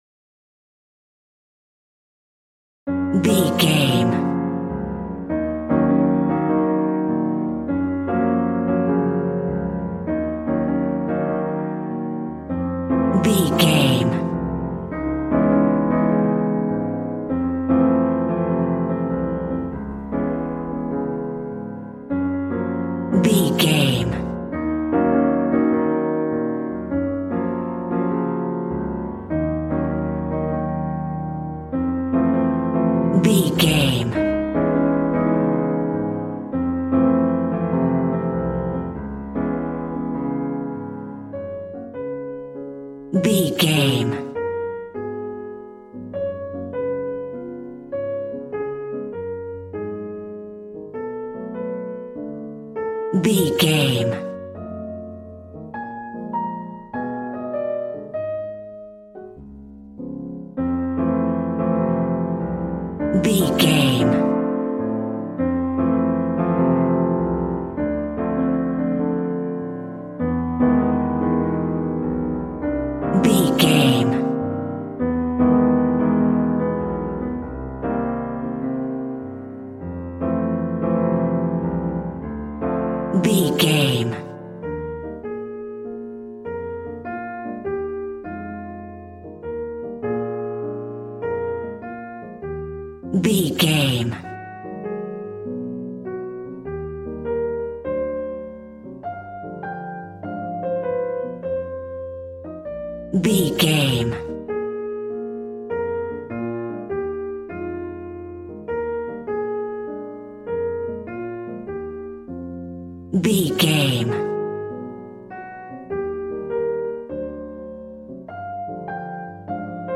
Smooth jazz piano mixed with jazz bass and cool jazz drums.,
Aeolian/Minor
B♭
piano
drums